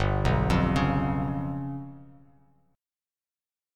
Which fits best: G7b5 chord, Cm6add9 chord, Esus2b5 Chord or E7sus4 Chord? G7b5 chord